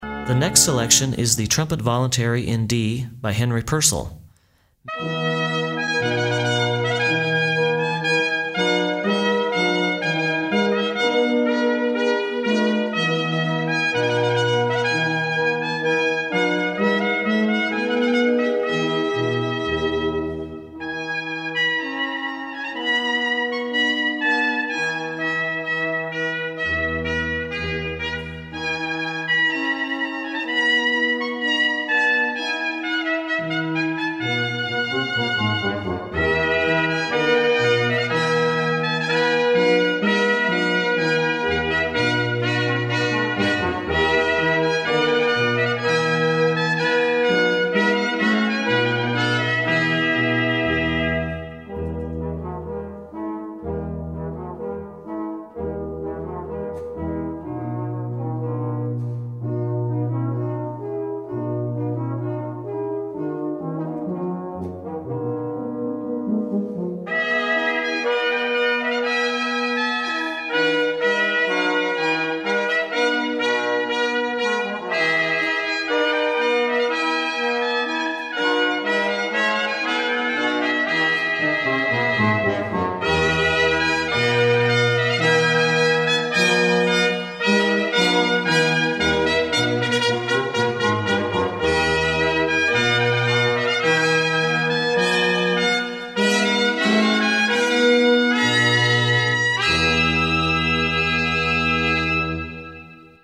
Denver brass quintet
The Peak Brass Quintet performs a wide repertoire of classical music.